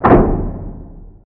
impact-5.mp3